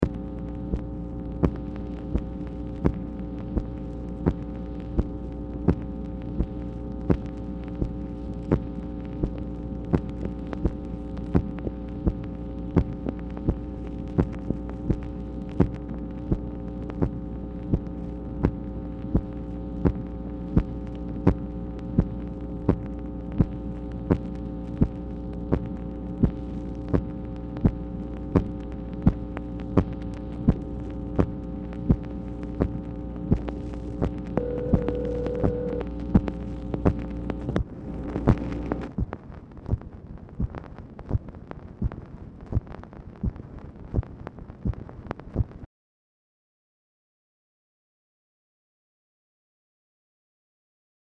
Telephone conversation # 10909, sound recording, MACHINE NOISE, 10/1/1966, time unknown | Discover LBJ
Format Dictation belt
White House Telephone Recordings and Transcripts Speaker 2 MACHINE NOISE